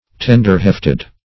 Search Result for " tender-hefted" : The Collaborative International Dictionary of English v.0.48: Tender-hefted \Ten"der-heft`ed\, a. Having great tenderness; easily moved.